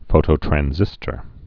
(fōtō-trăn-zĭstər)